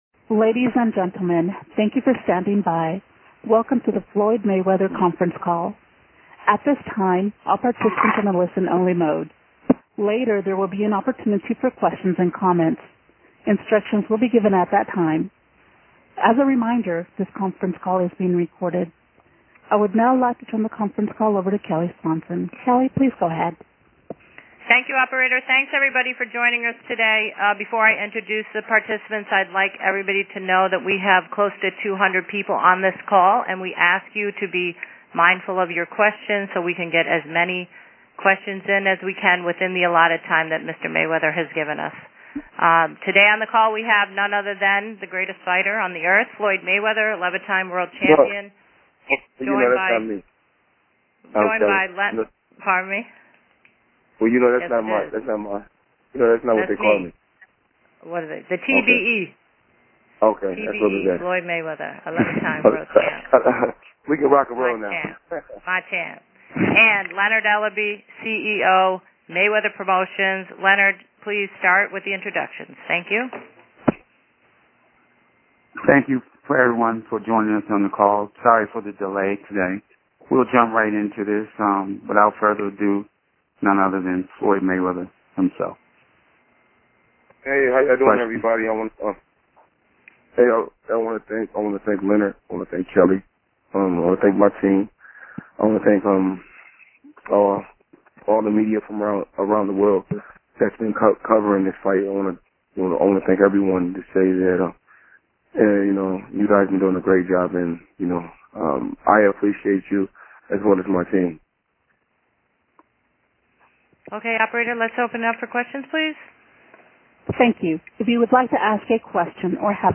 Click for full Audio of the Floyd Mayweather Jr Conference Call FLOYD MAYWEATHER CONFERENCE CALL
FLOYD-MAYWEATHER-CONFERENCE-CALl.mp3